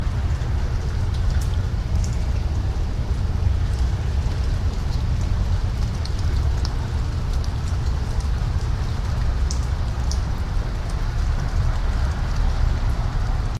Recording 1 is the original recording (from our class), with all its dynamics and